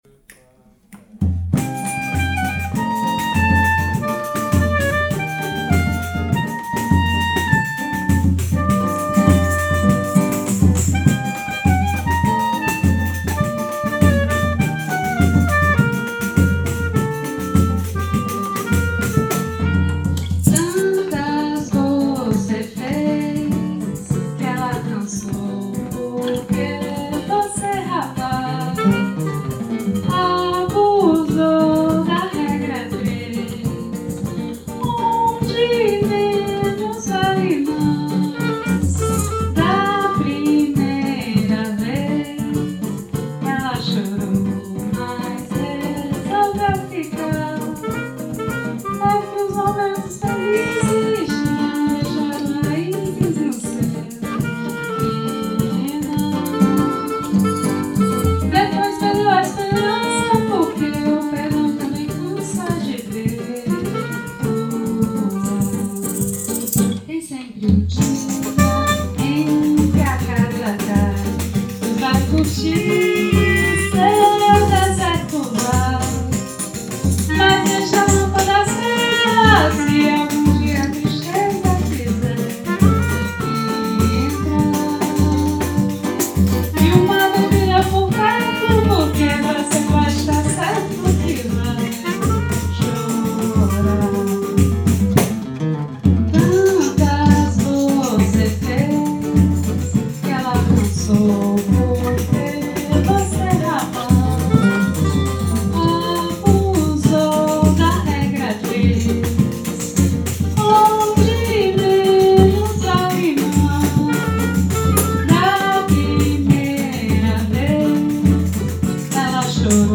Rec atelier
à la guitare